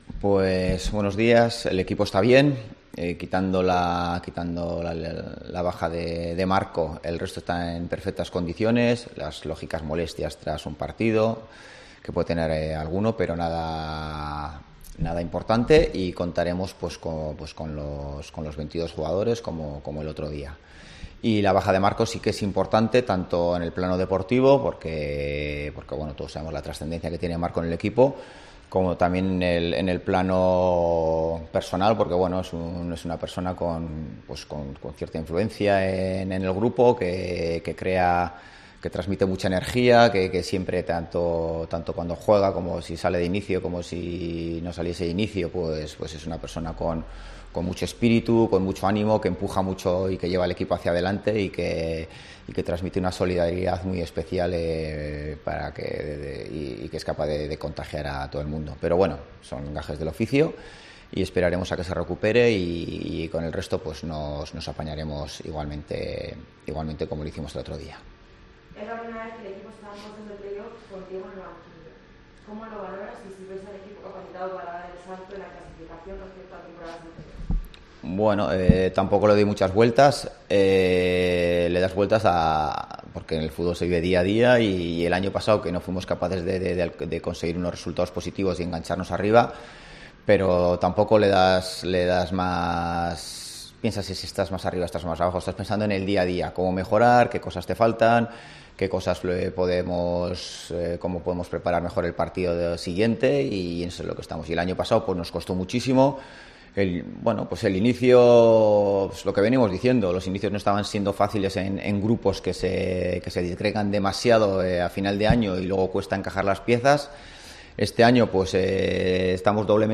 Rueda de prenda Ziganda (previa Ibiza-Oviedo)